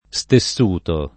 SteSS%i]; part. pass. stessuto [